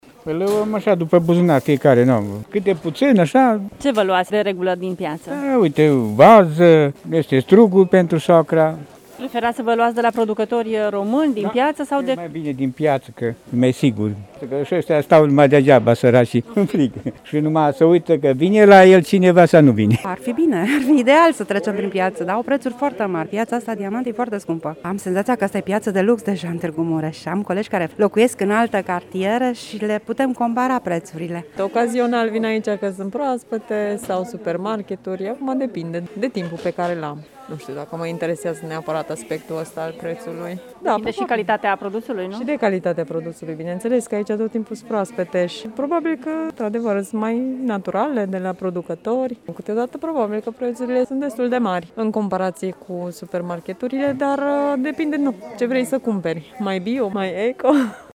Unii oameni preferă totuși să cumpere produse din piață, unde sunt mai multe șanse să fie bio: